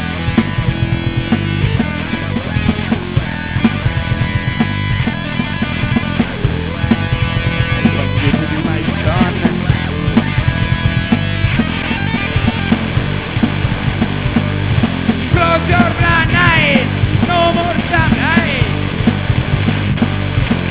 Metal